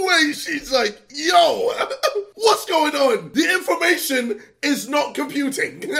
Instant meme sound effect perfect for videos, streams, and sharing with friends.